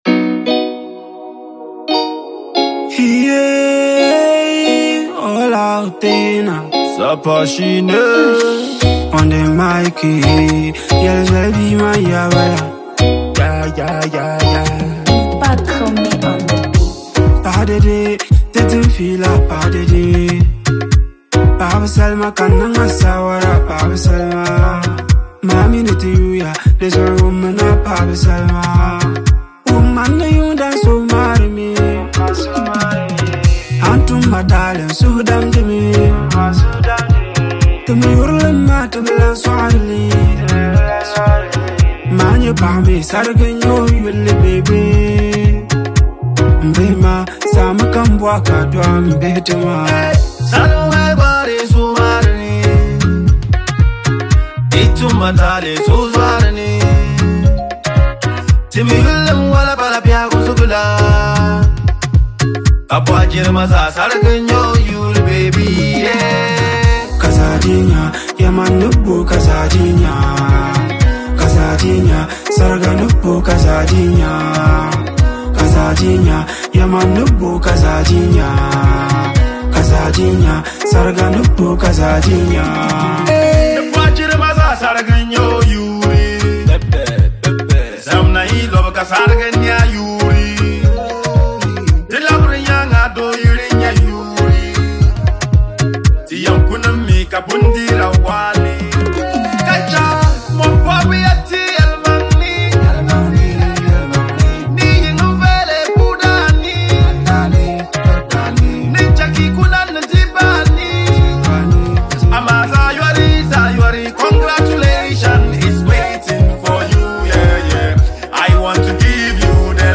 rap musician
catchy tune